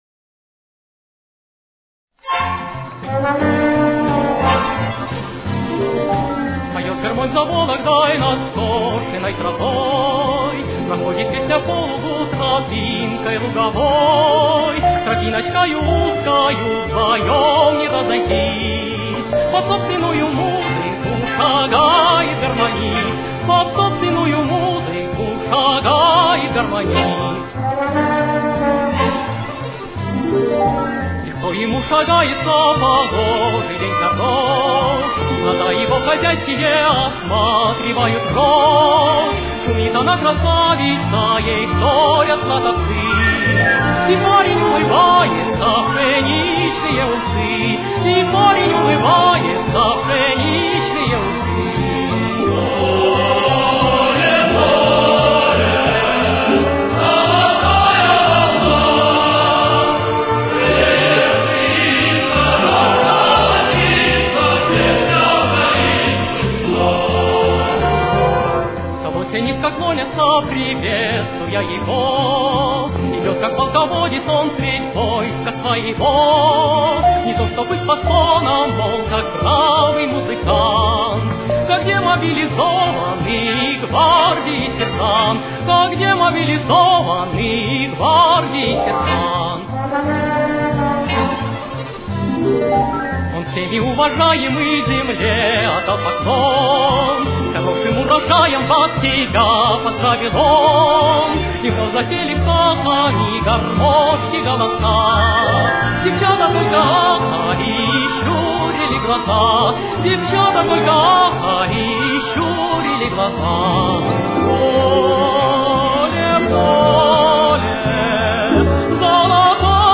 Фа мажор. Темп: 177.